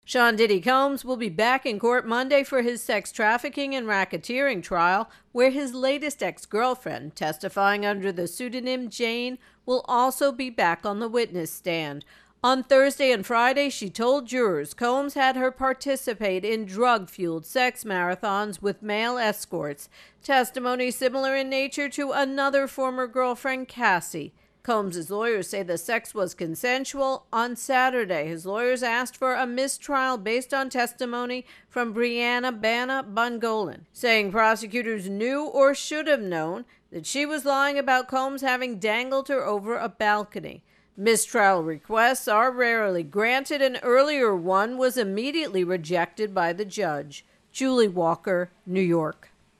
reports on the Sean 'Diddy' Combs sex trafficking and racketeering trial.